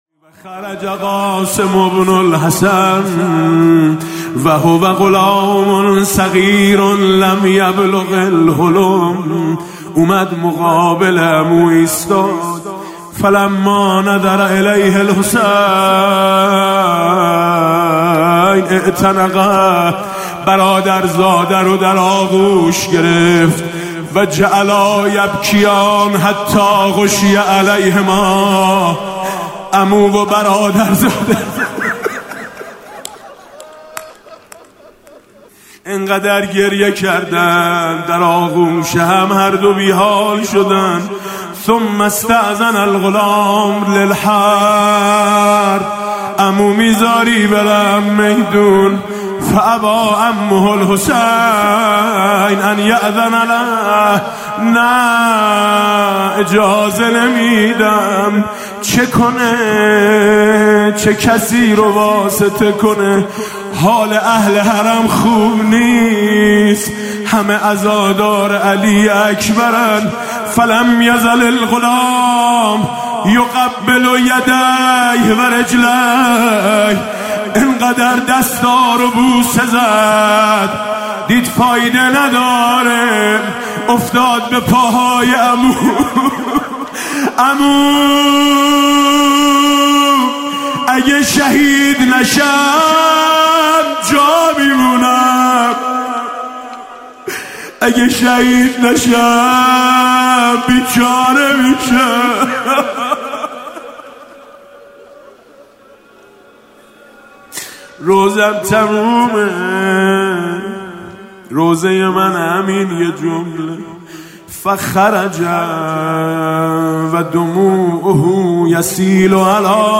روضه شب ششم محرم
روضه شب ششم محرم حاج میثم مطیعی روضه حضرت قاسم بن الحسن علیهماالسلام | دست و پای عمو را بوسید..